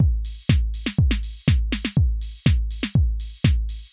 AIR Beat - Mix 3.wav